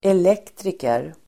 Ladda ner uttalet
elektriker.mp3